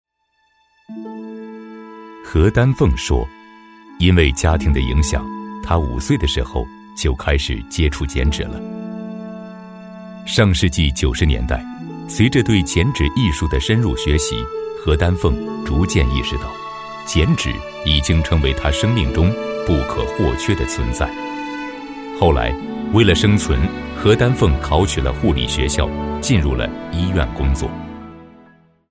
人物专题配音